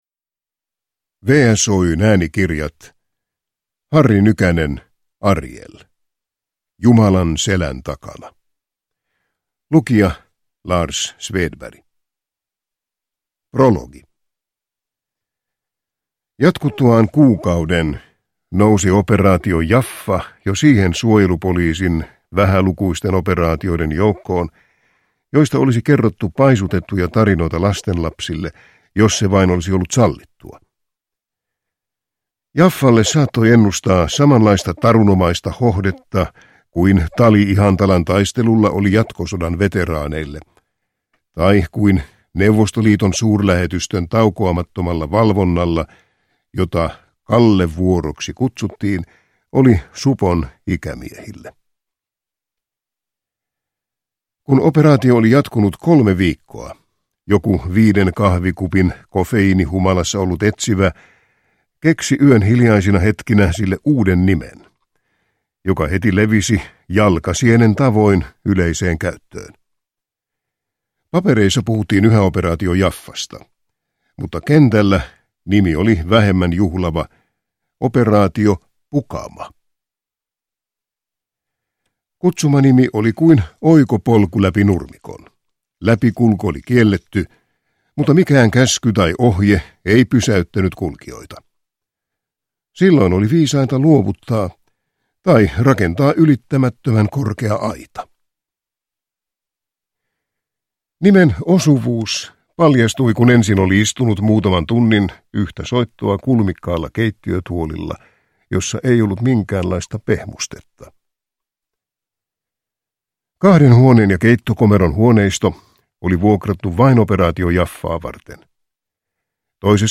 Jumalan selän takana – Ljudbok – Laddas ner